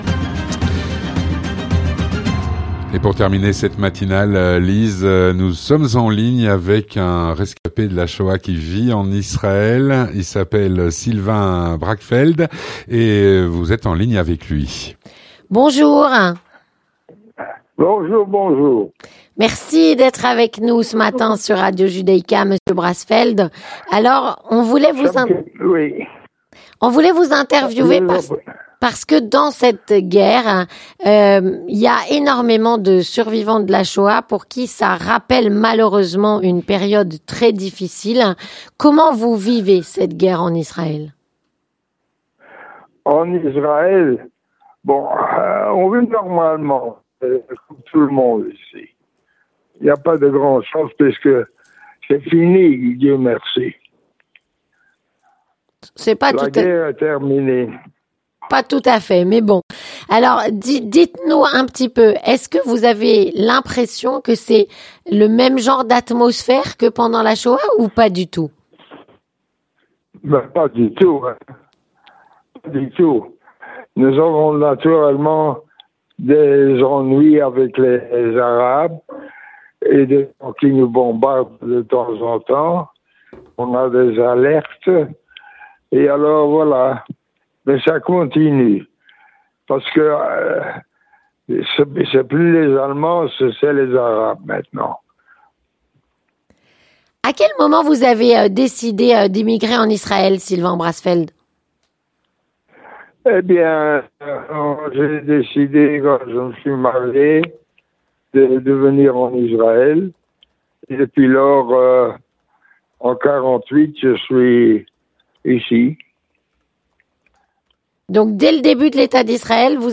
4. Témoignage - Comment ressent-on les massacres du 07 Octobre quand on est rescapé de la Shoah ?